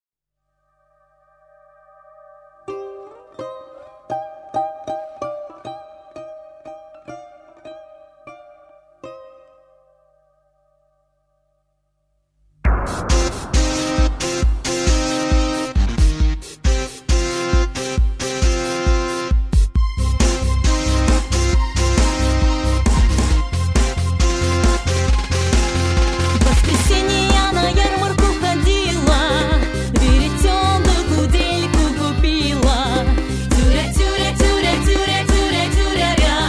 РУССКОГО ФОЛЬКЛОРНО-ЭСТРАДНОГО АНСАМБЛЯ